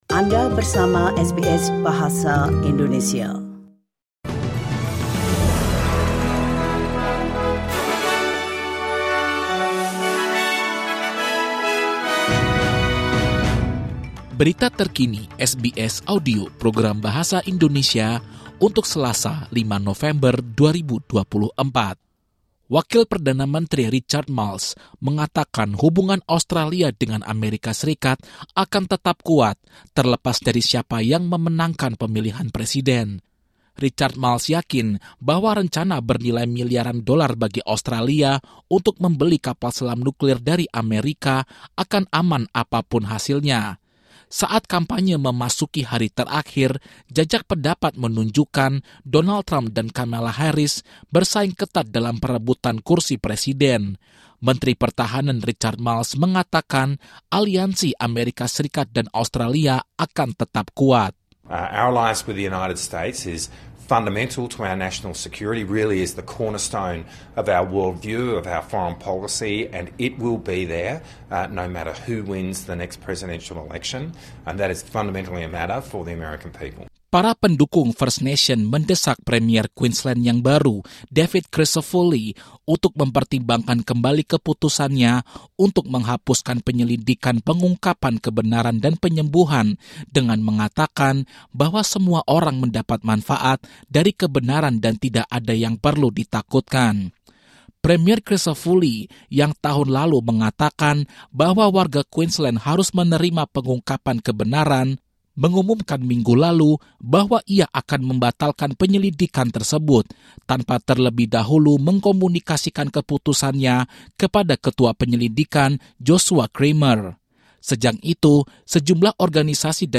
Latest News SBS Audio Indonesian Program - 5 November 2024